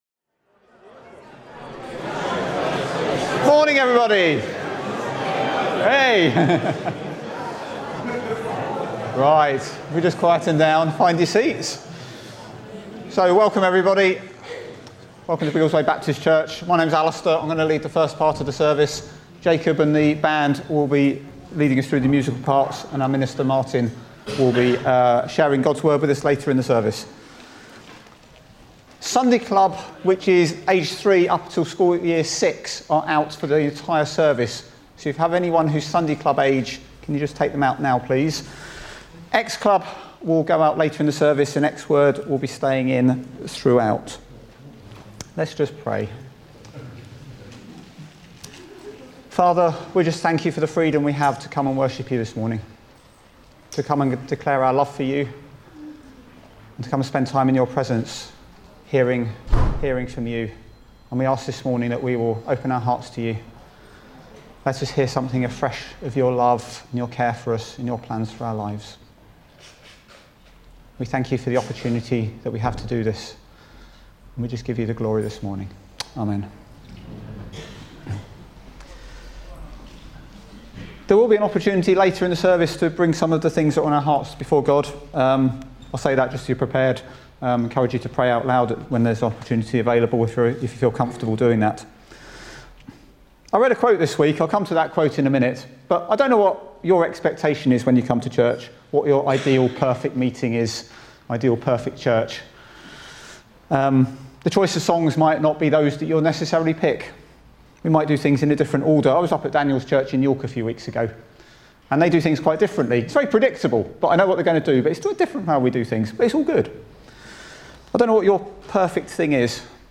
1 March 2026 – Morning Service